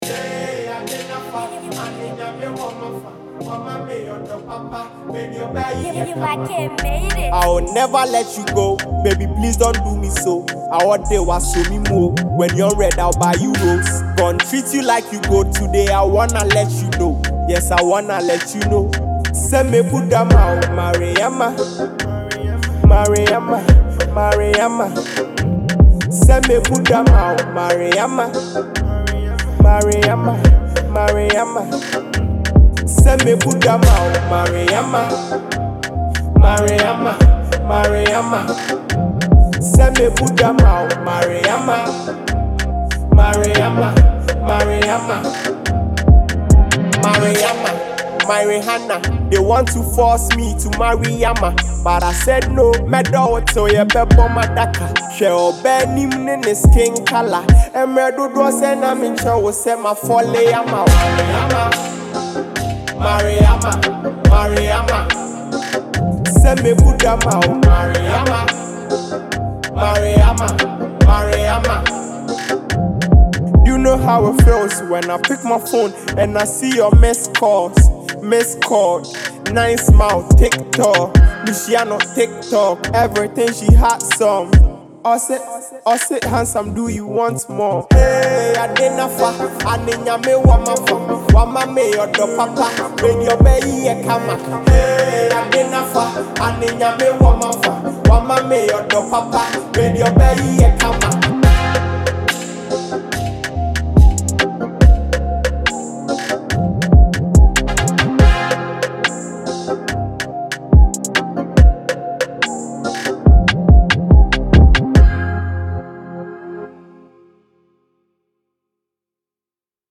Known for her warm vocals and heartfelt delivery